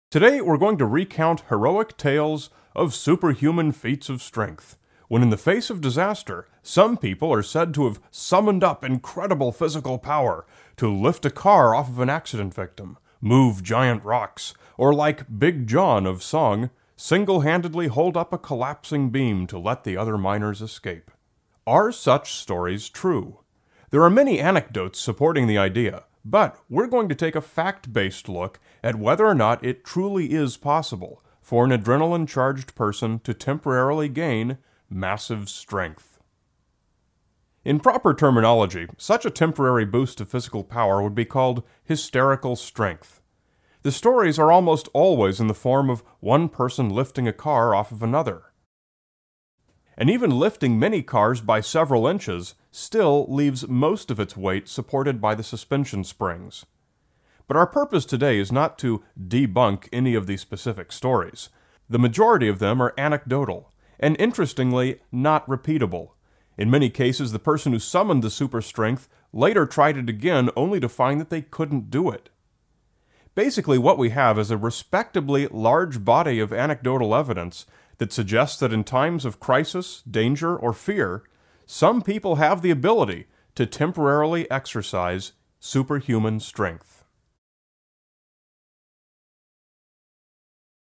PTE Top 3 Frequently Asked Retell Lecture Questions (April 2020)